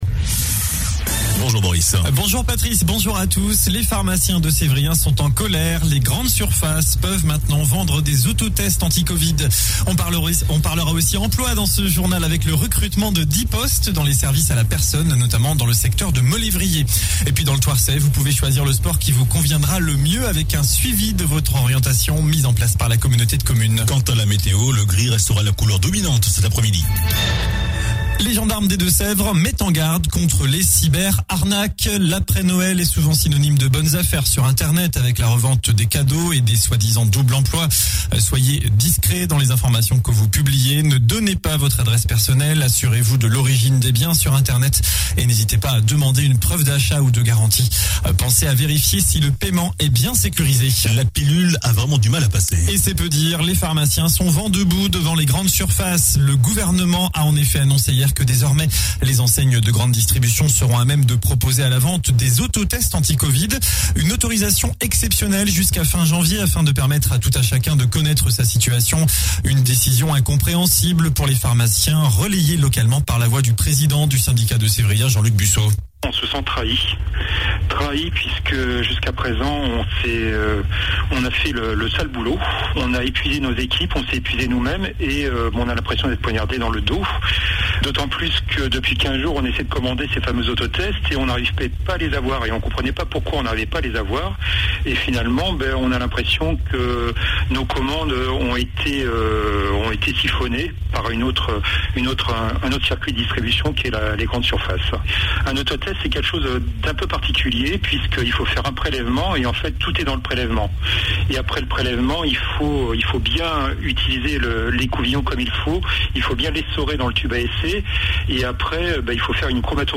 JOURNAL DU MERCREDI 29 DECEMBRE ( MIDI )